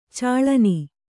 ♪ cāḷani